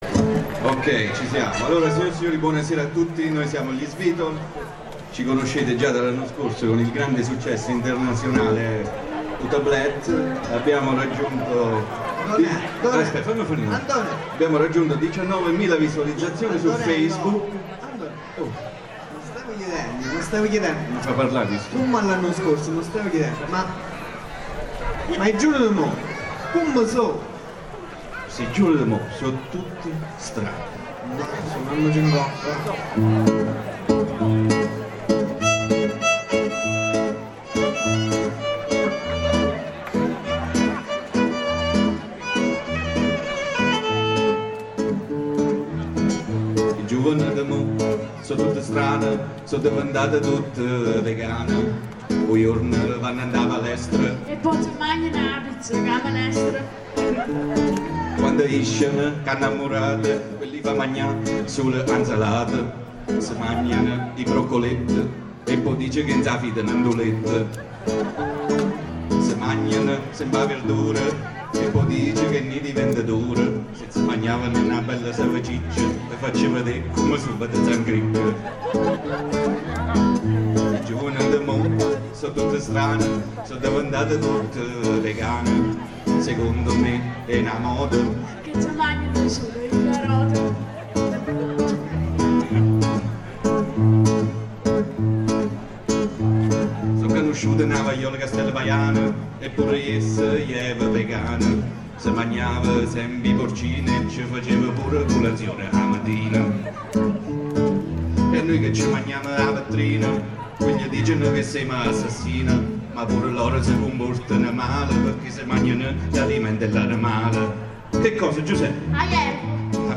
Festival Della Canzone Dialettale Molisana 2018: Serate Premorienza
Le pochissime canzoni, da me registrate senza alcuna vera voglia di farlo, sono state posizionate alla rinfusa.